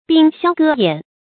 兵銷革偃 注音： ㄅㄧㄥ ㄒㄧㄠ ㄍㄜˊ ㄧㄢˇ 讀音讀法： 意思解釋： 銷毀兵器，放下甲盾。指太平無戰事。